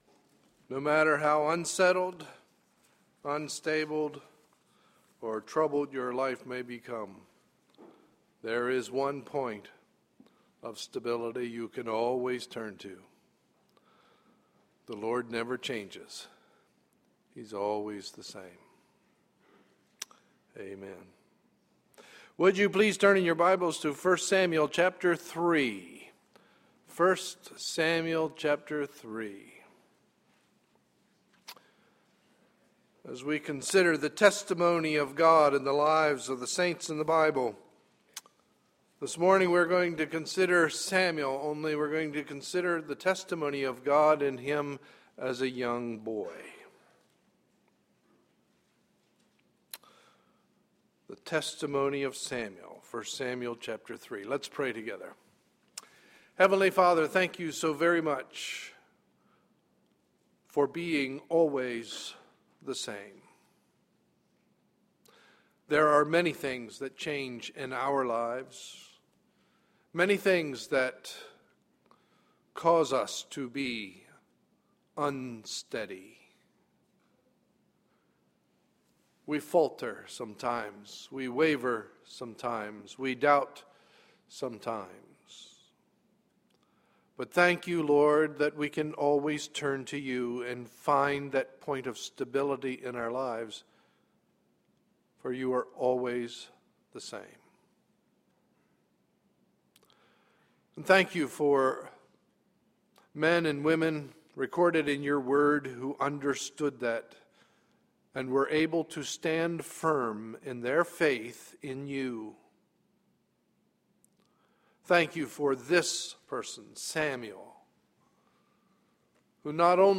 Sunday, May 27, 2012 – Morning Message